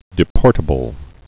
(dĭ-pôrtə-bəl)